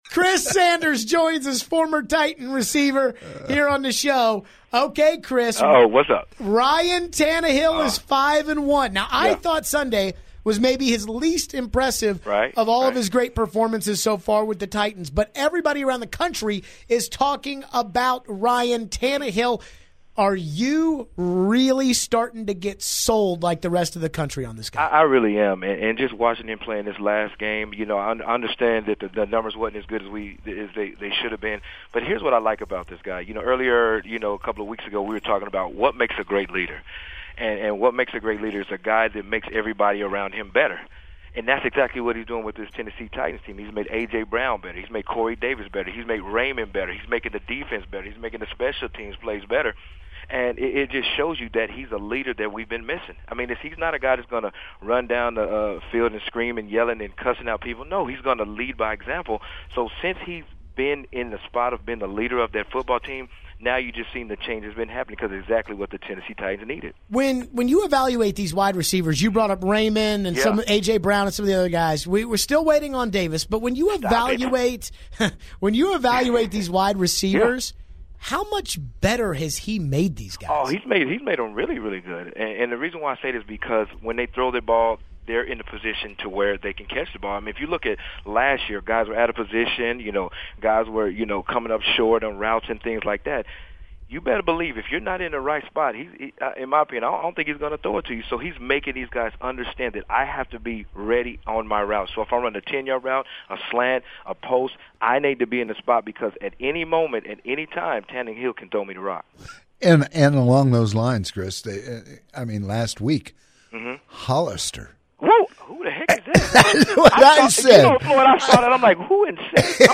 Chris Sanders, former Titans receiver, calls in for his weekly visit to talk Titans and Tannehill.